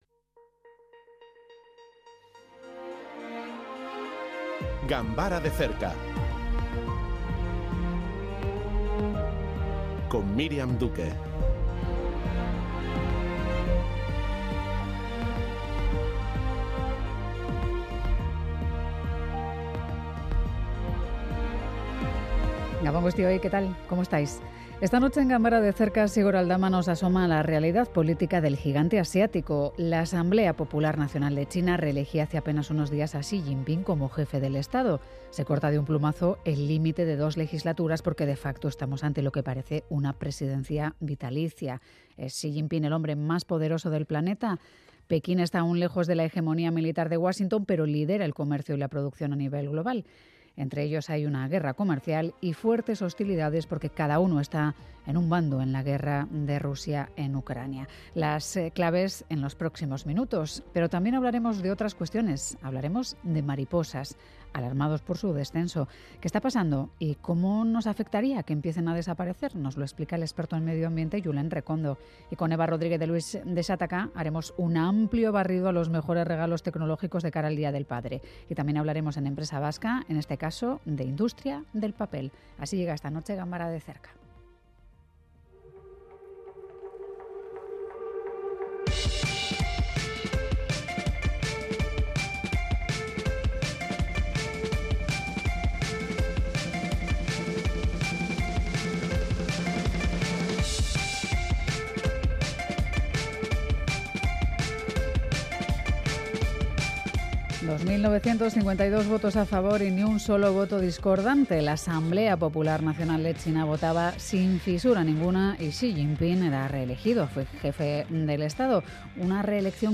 Informativo que analiza con detalle temas de actualidad